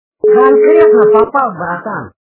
» Звуки » Смешные » Голос - Конкретно попал, братан
Звук Голос - Конкретно попал, братан